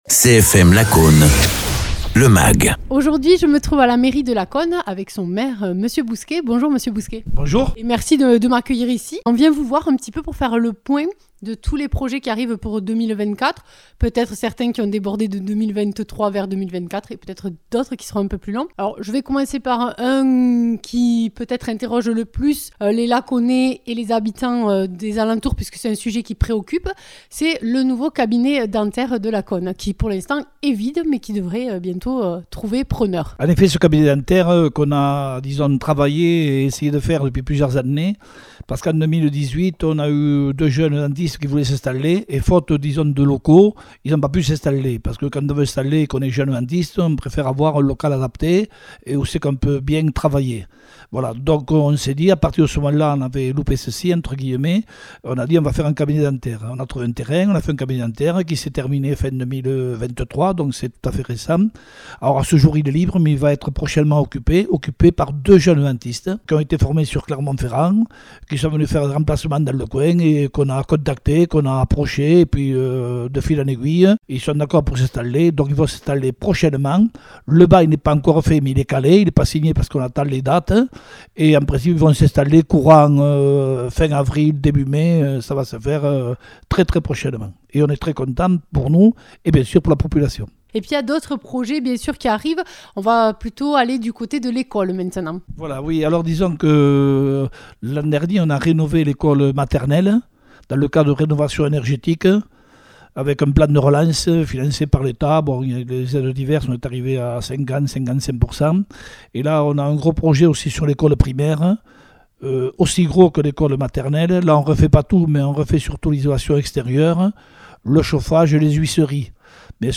Interviews
Invité(s) : Robert Bousquet, maire de Lacaune-les-Bains (Tarn)